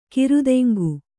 ♪ kirudeŋgu